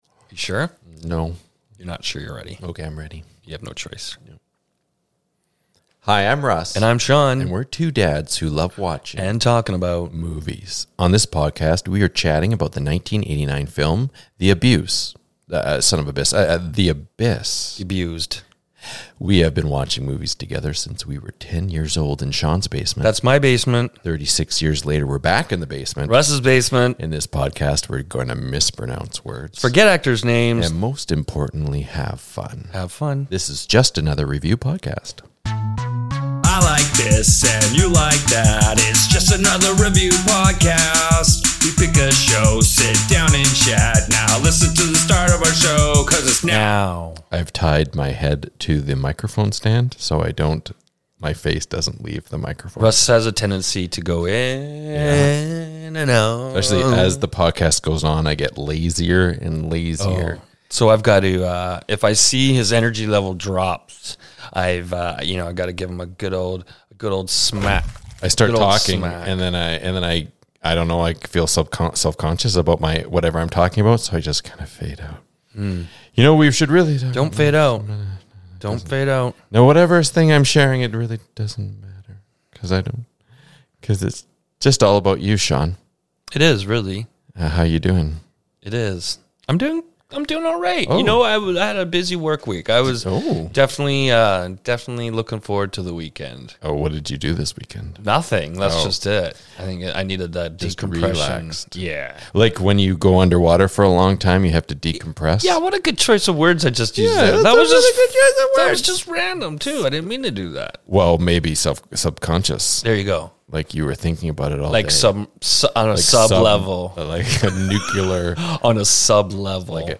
The two B!tches mispronounce words, forget actors names, and most importantly have fun. This is Just Another Review Podcast (or JARP for short).